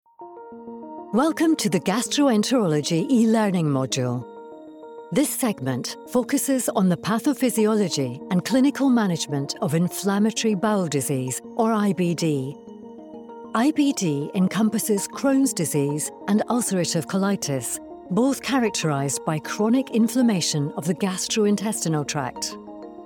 Narration médicale
J'ai un léger accent écossais et ma voix a été décrite comme sophistiquée, douce, chaleureuse et autoritaire.
Microphone Sennheiser
Anglais (écossais)
Âge moyen